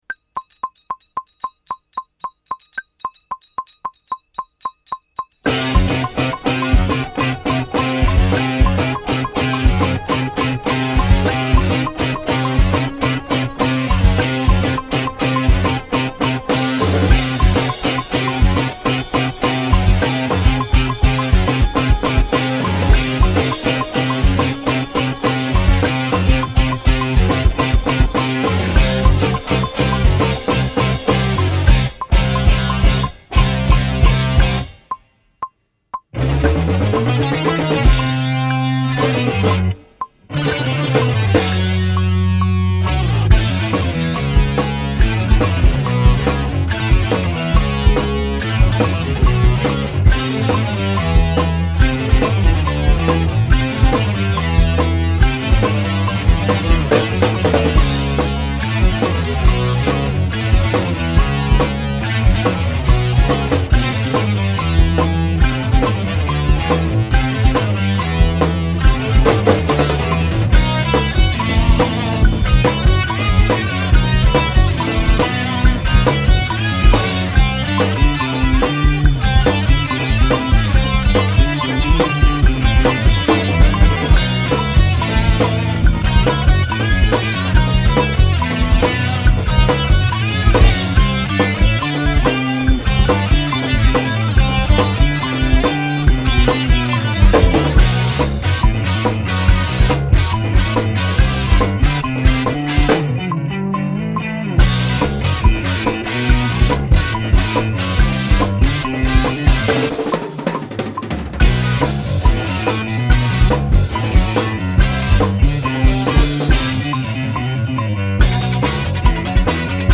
Progressive Rock
Studioproduktion | Musikmesse Frankfurt